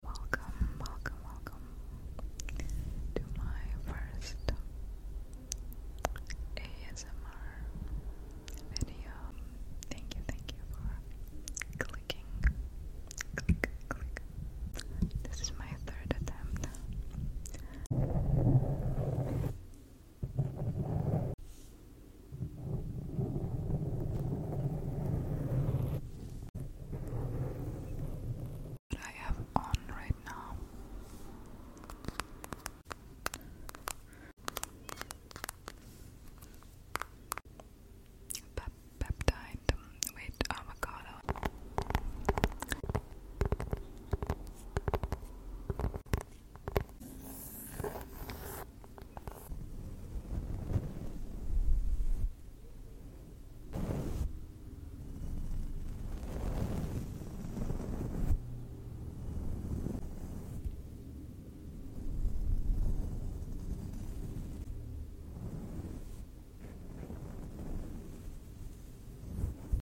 TB To My First Asmr Sound Effects Free Download